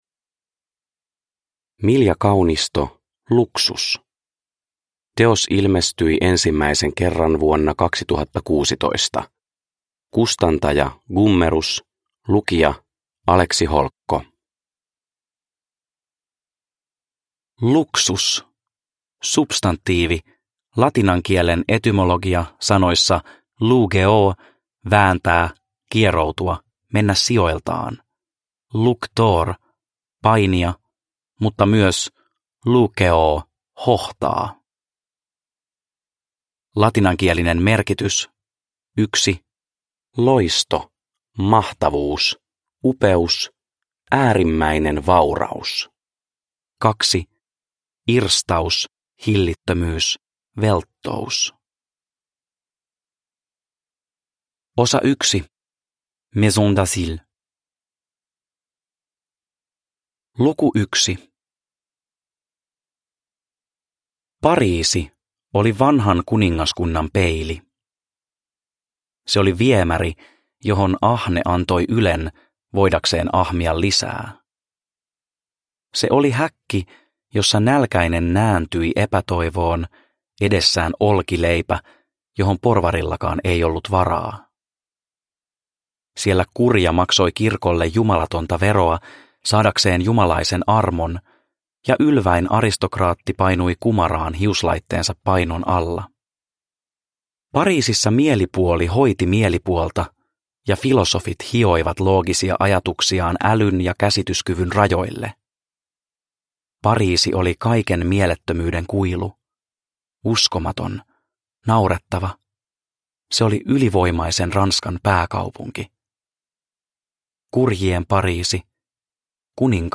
Luxus – Ljudbok – Laddas ner